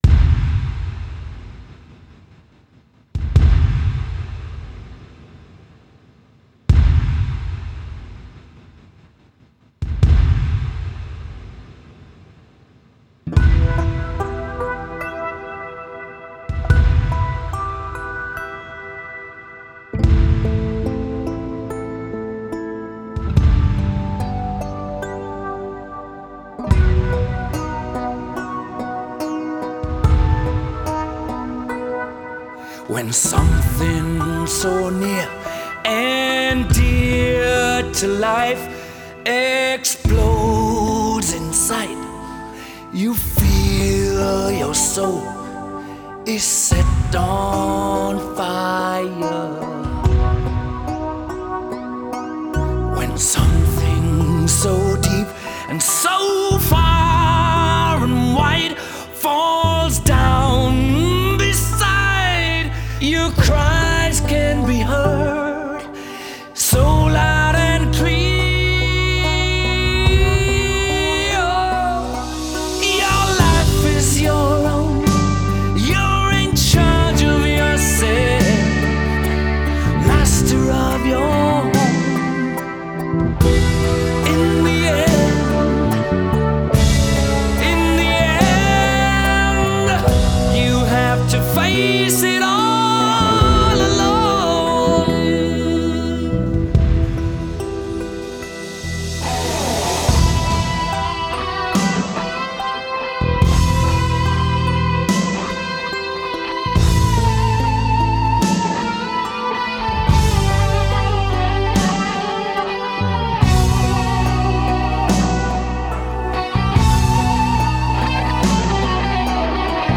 به کمک تکنولوژی جدید دوباره بازسازی‌شده